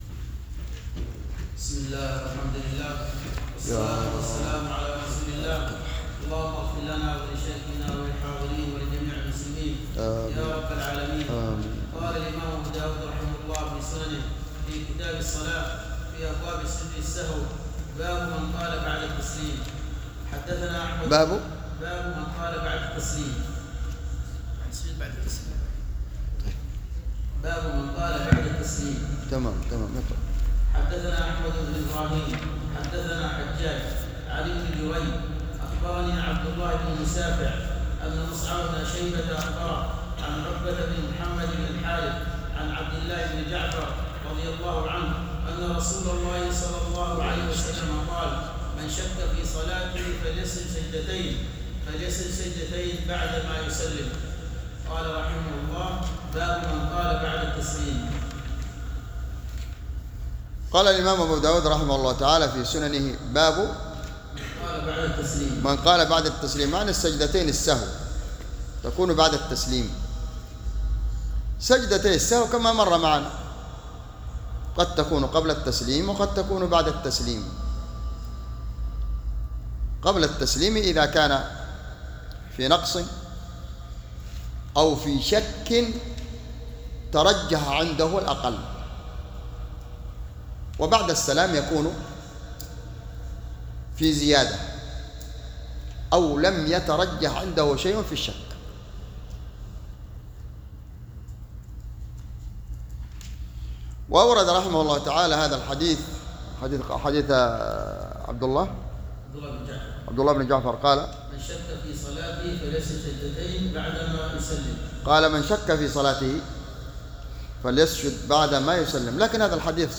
يحتوي على جميع الدروس الصوتية والمقالات والكتب وجدول الدروس اليومية وكل ما قد يُستفاد منه لطلاب وطلبة العلم